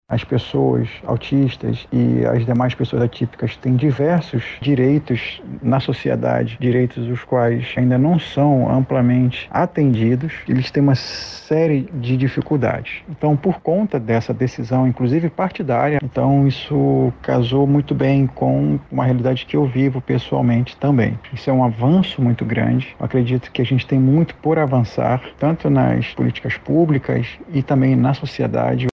SONORA-2-FRENTE-PARLAMENTAR-AUTISTAS-.mp3